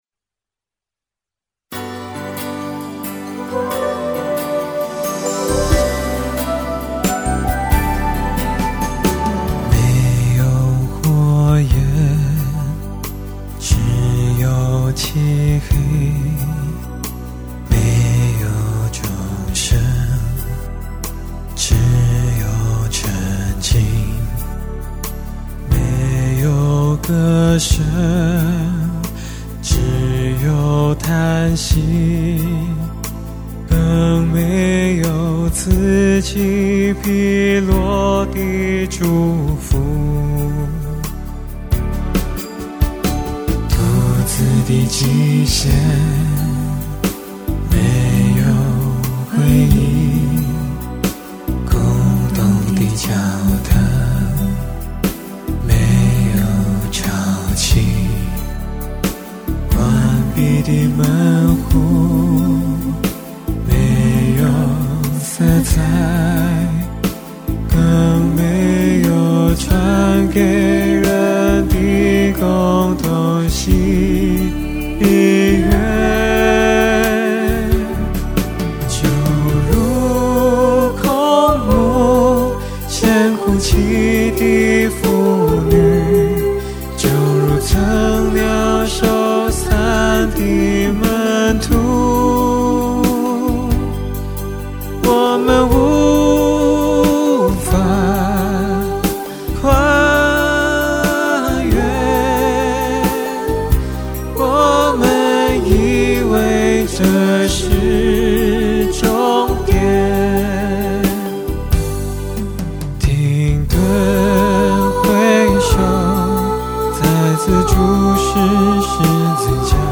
这首歌由原本的无奈、感伤，到充满希望。